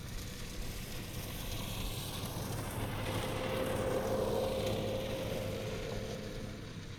Zero Emission Subjective Noise Event Audio File (WAV)